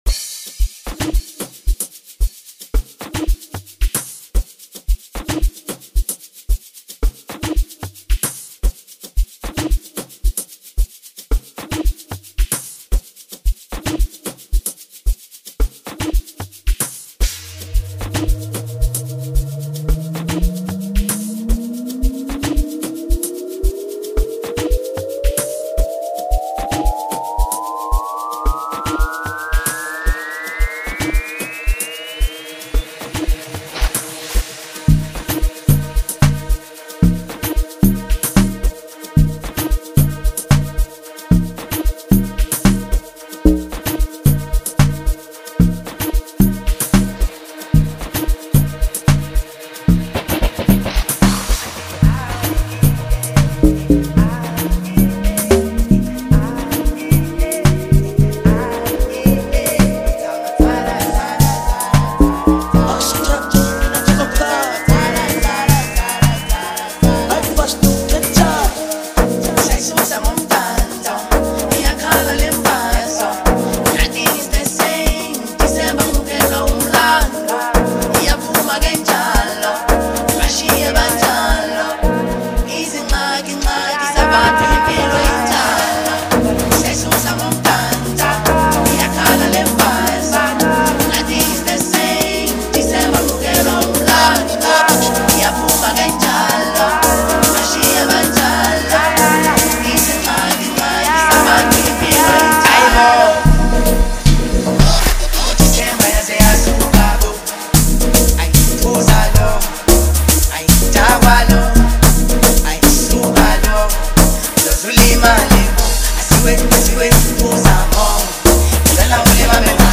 2023 Categoria: Amapiano SPOTIFY Download RECOMENDAÇÕES